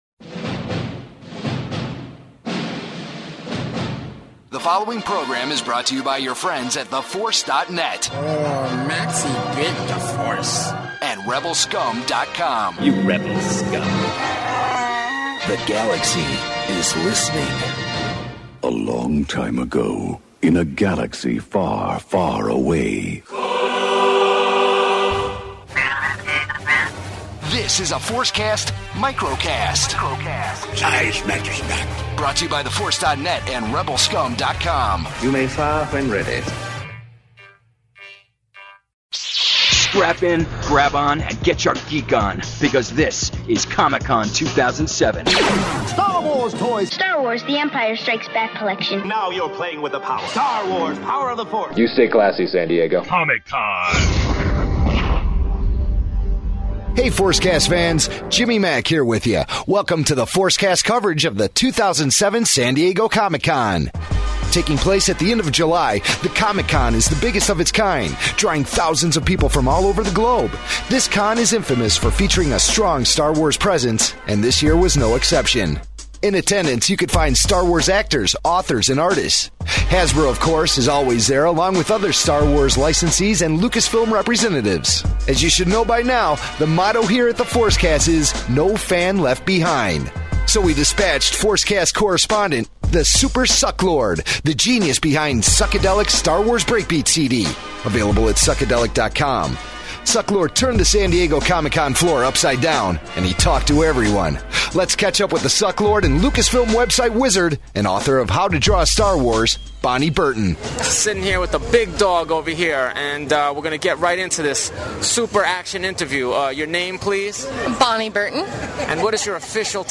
Ainda não está esquecida a convenção San Diego Comic-Con deste ano. No segundo Microcast desta semana a equipa habitual traz-nos diversas entrevistas conduzidas